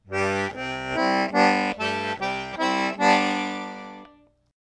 That is, two notes are being played on the right side while only one is being played on the left.
A pull & push on the bass button, then a push & pull on the chord button.